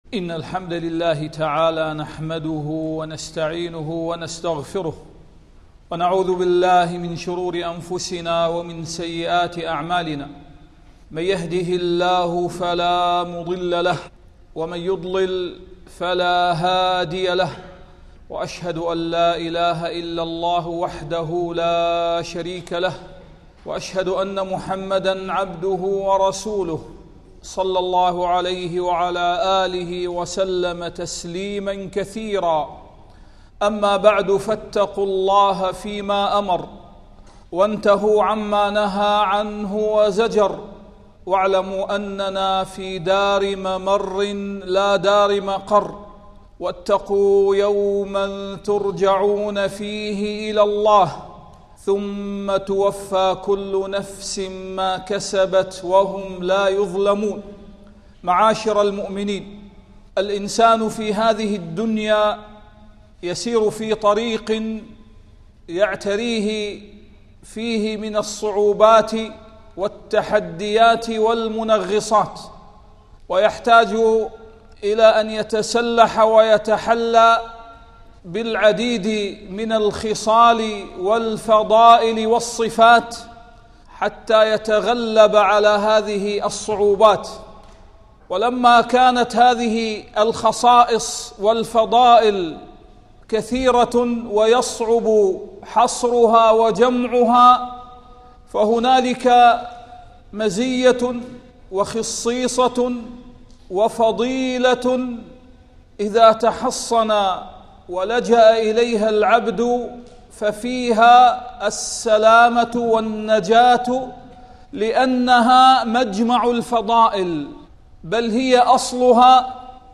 قال بعض الحكماء: الصدق منجيك وإن خفته، والكذب مرديك وإن أمنته.. التصنيف: خطب الجمعة